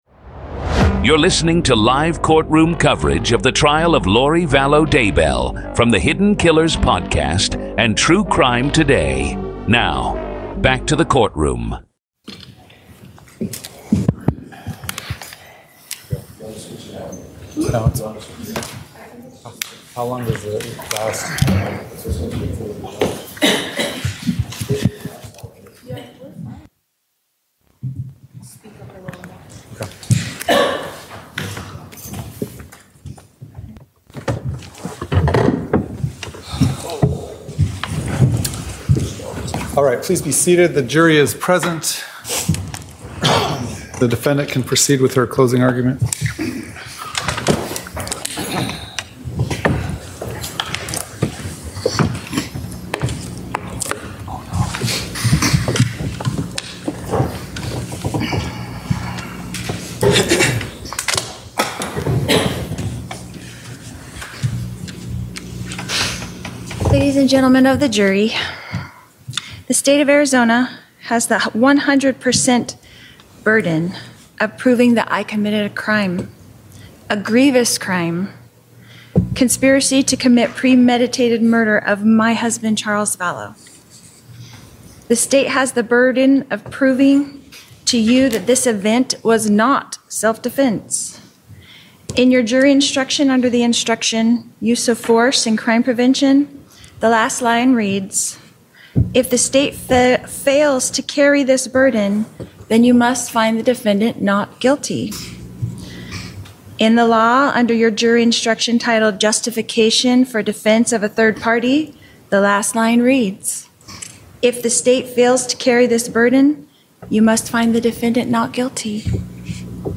In this episode, you’ll hear the raw courtroom audio from closing arguments in Lori Vallow Daybell’s murder trial—starting with the prosecution’s detailed breakdown of a calculated plan fueled by money, religious delusion, and conspiracy. You’ll also hear Lori herself deliver her own closing argument, defending her actions in a final attempt to sway the jury. Representing herself at trial, Daybell faces charges for the murder of her fourth husband, Charles Vallow.